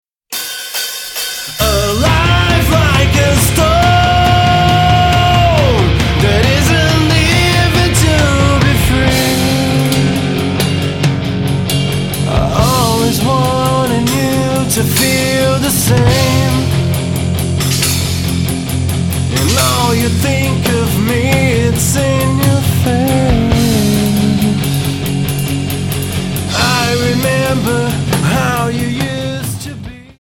Alternative,Rock